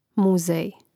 mùzēj muzej